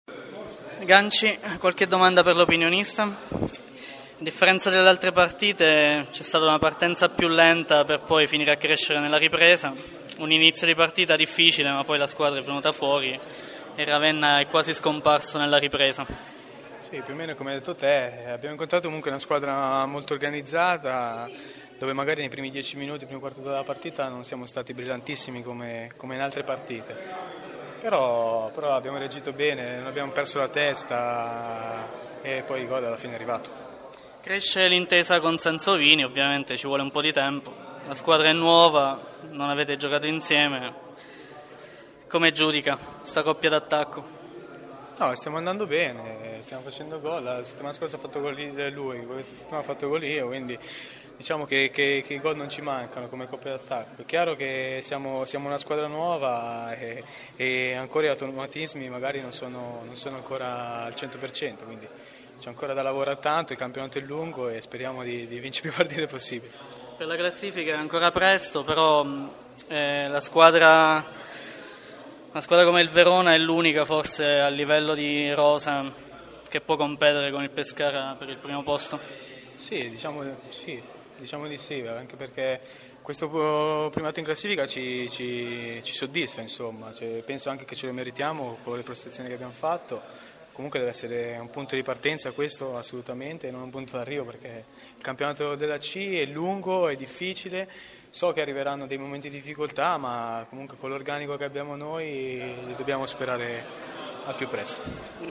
Interviste dopo partita: Pescara-Ravenna